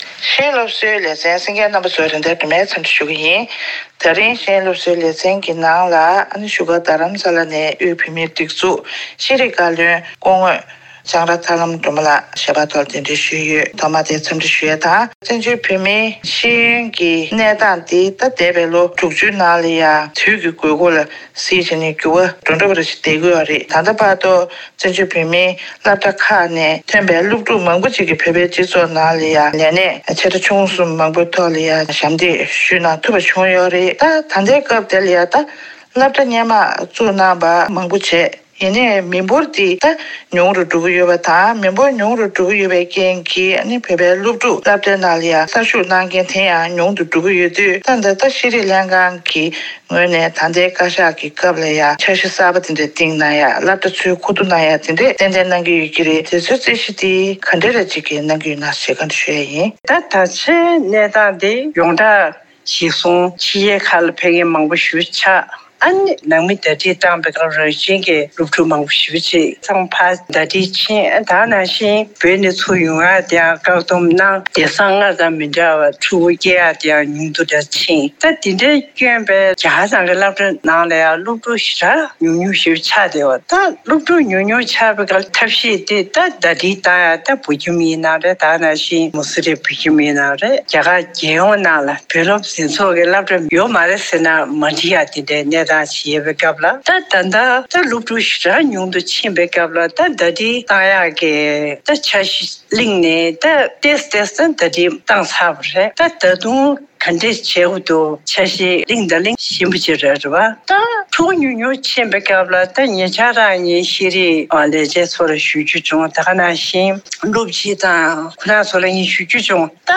དམིགས་བསལ་གནས་འདྲི་ཞུས་པ་ཞིག་ལ་གསན་རོགས་ཞུ།